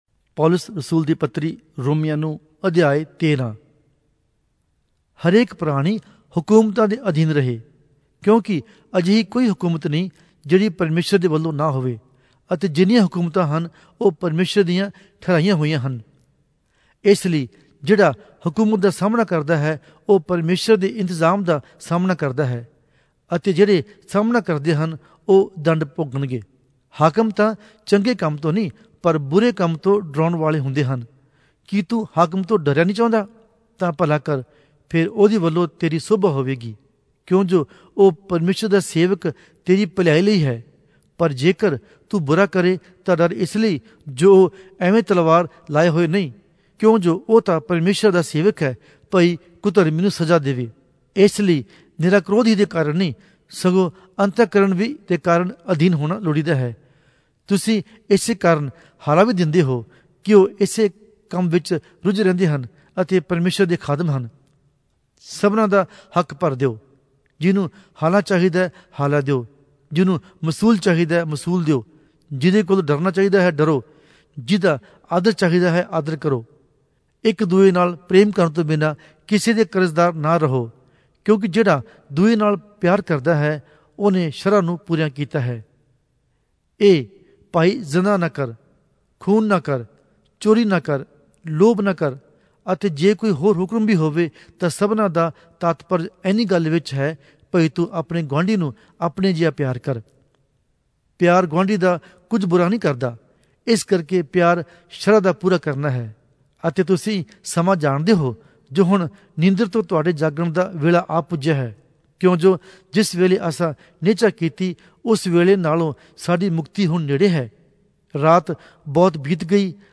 Punjabi Audio Bible - Romans All in Irvte bible version